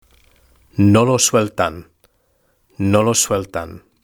Lectura en voz alta: 3.2 Los medios de comunicación y la tecnología (H)